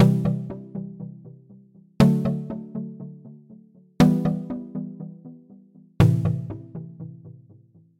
Tag: 120 bpm Chill Out Loops Piano Loops 1.35 MB wav Key : Unknown